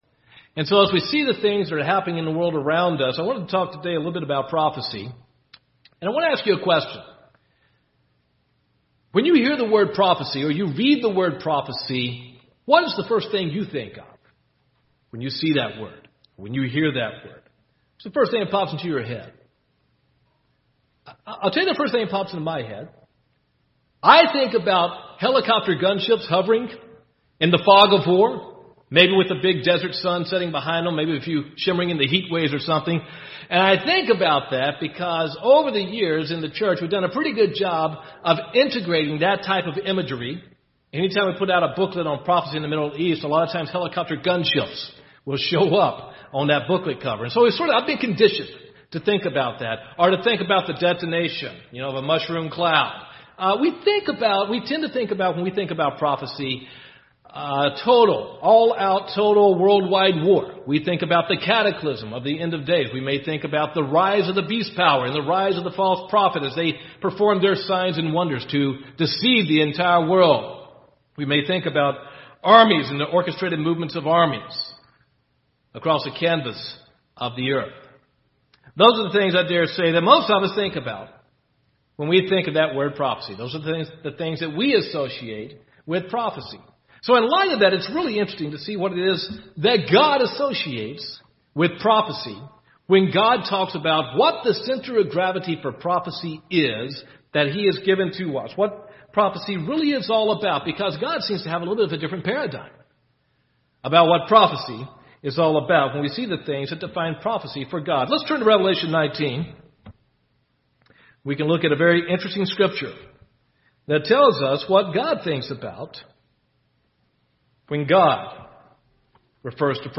The work the Father is doing through Christ ultimately brings about the restitution of all things. This sermon discusses many works that God begins in Genesis and completes in Revelation.
Given in Gadsden, AL